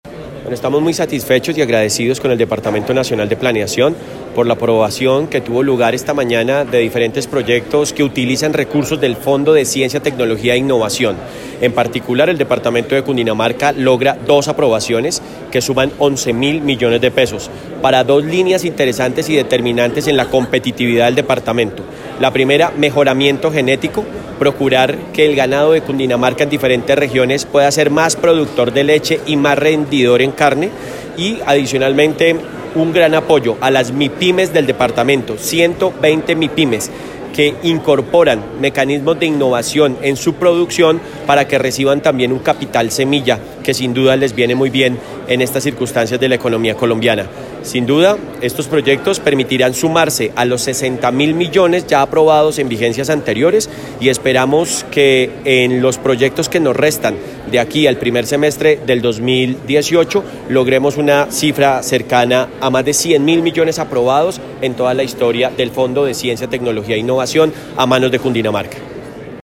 Entrevista Gobernador Jorge Emilio Rey Ángel
Audio+Gobernador+Jorge+Rey.mp3